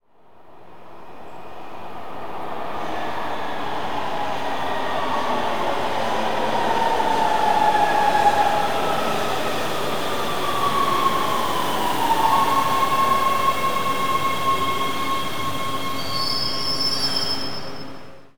arrive.ogg